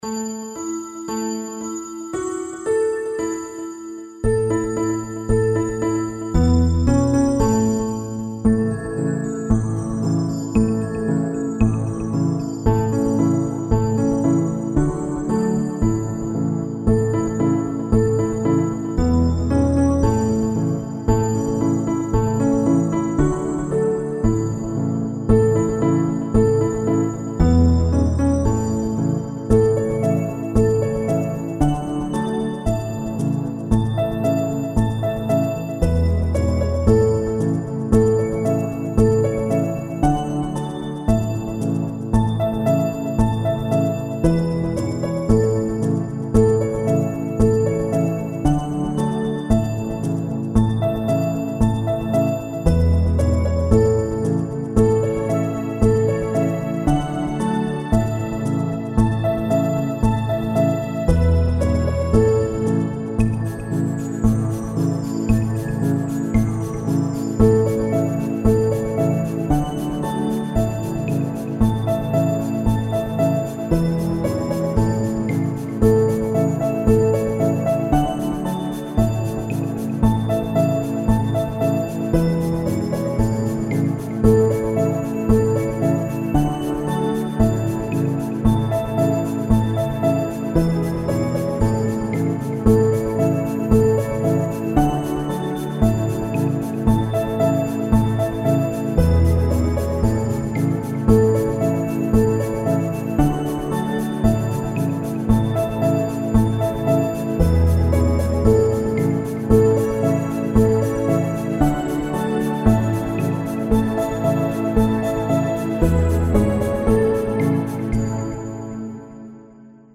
Nursery rhyme, England (UK)
Kids Karaoke Song (Instrumental) YouTube License